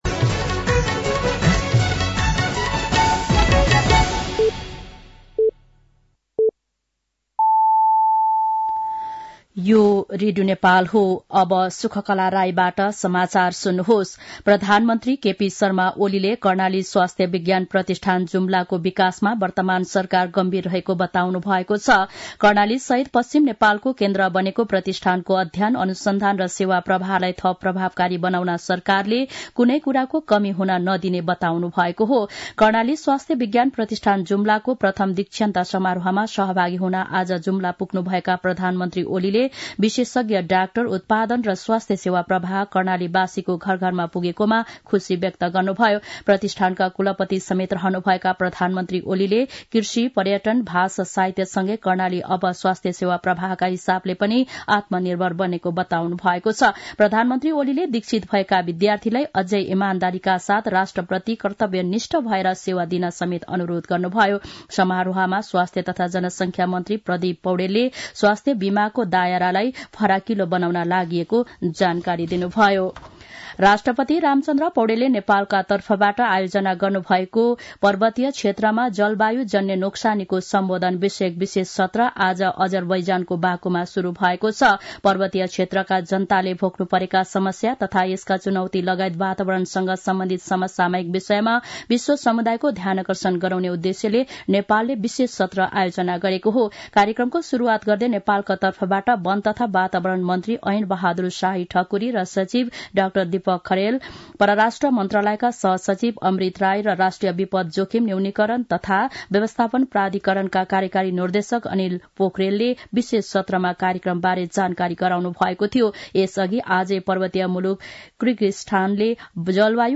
साँझ ५ बजेको नेपाली समाचार : २९ कार्तिक , २०८१
5-Pm-nepali-news-7-28.mp3